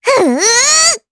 Estelle-Vox_Casting2_jp.wav